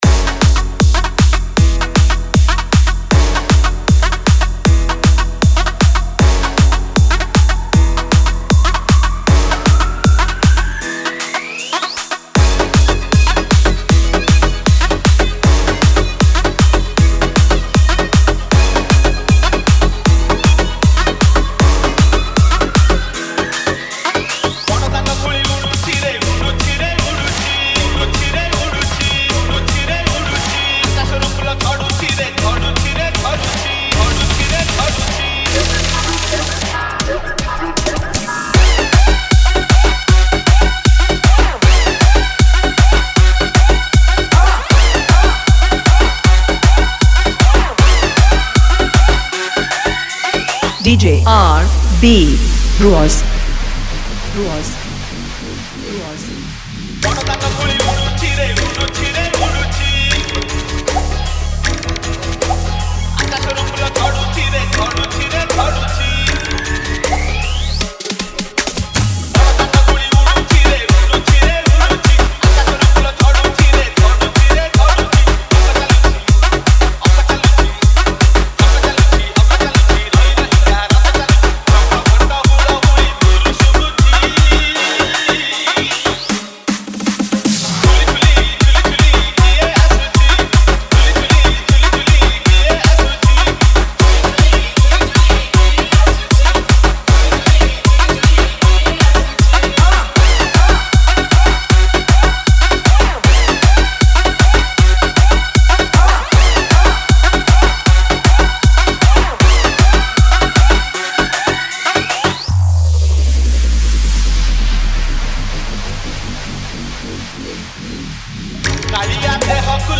Bhajan Dj Song Collection 2021